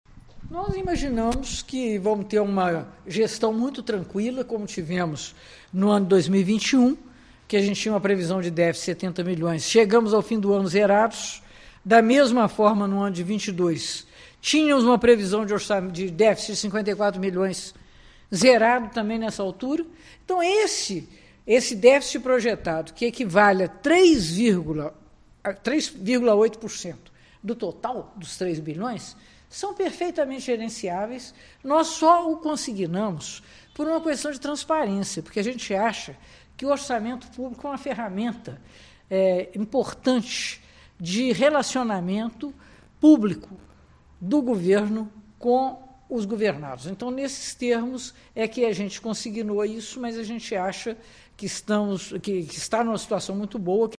A prefeita de Juiz de Fora, Margarida Salomão (PT) concedeu entrevista à imprensa para falar sobre o orçamento do executivo municipal e dar um panorama da gestão.
02-Entrevista-Margarida-1.mp3